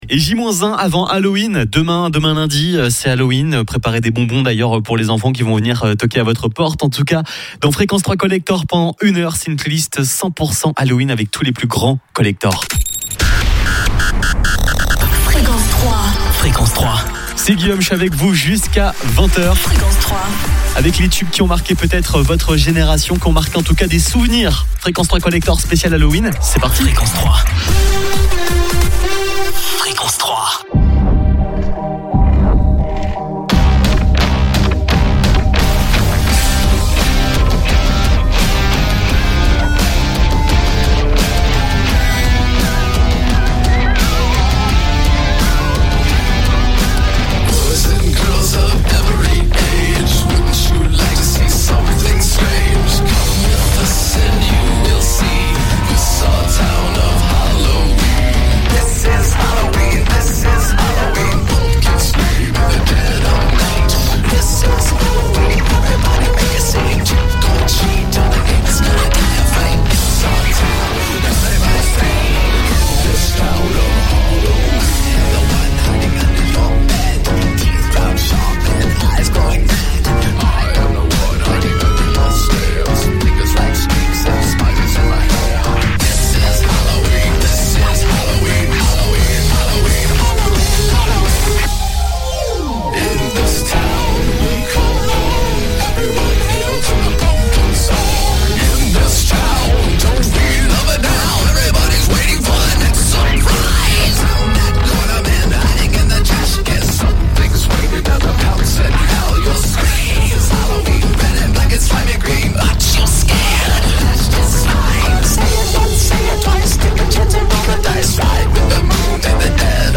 Fréquence 3 Collector : Des années 70 à début 2010, c'est tous les plus grands tubes qui ont marqué des générations.